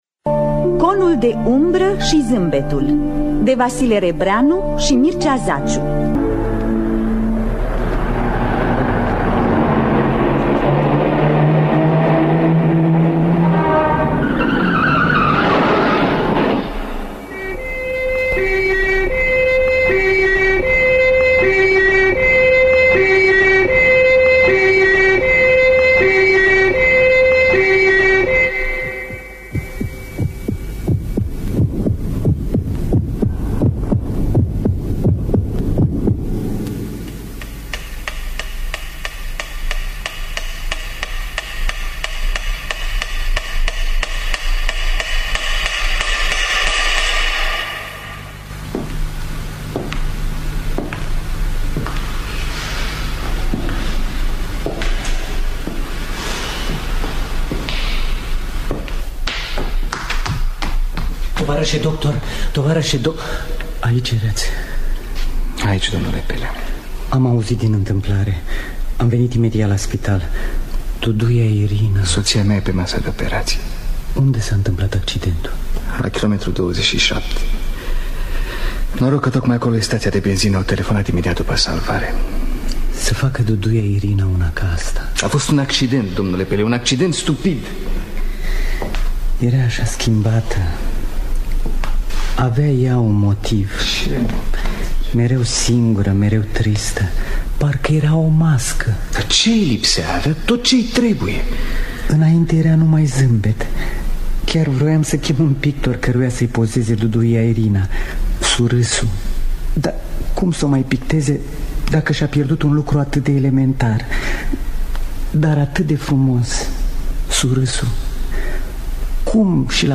Conul De Umbra și Zâmbetul – Vasile Rebreanu și Mircea Zaciu – Teatru Radiofonic Online